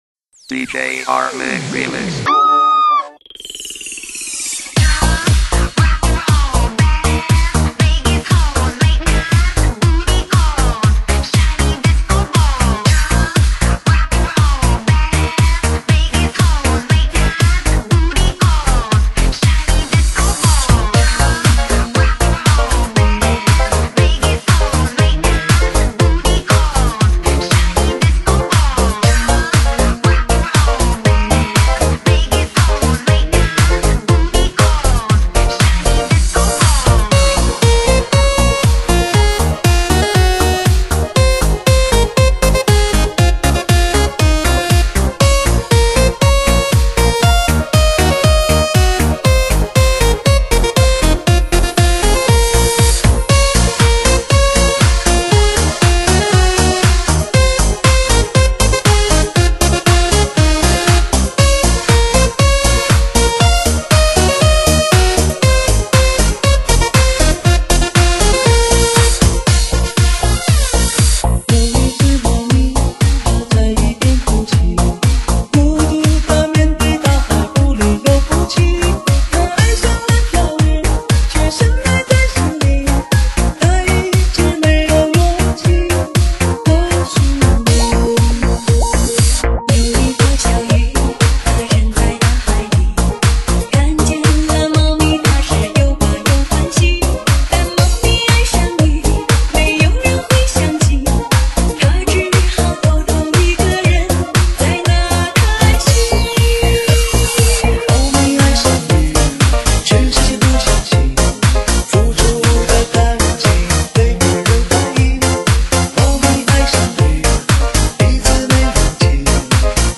发烧级车载必备音乐大碟
嗨翻的感觉让你颤抖